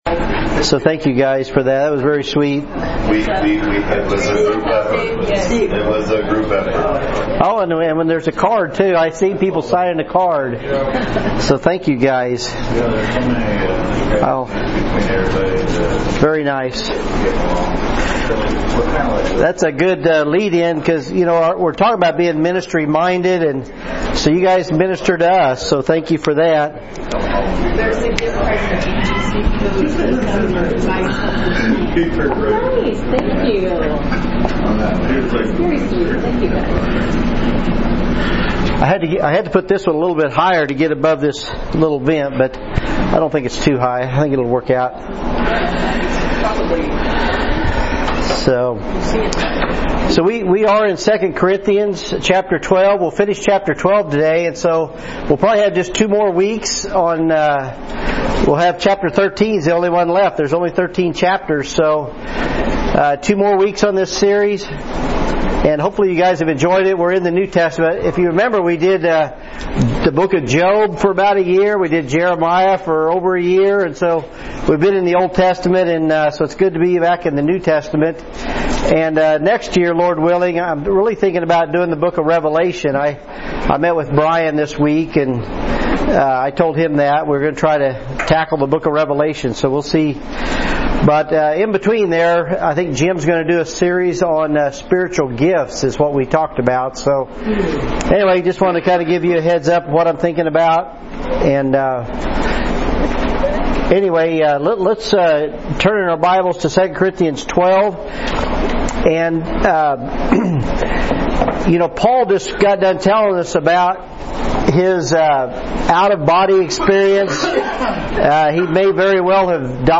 Current Sermon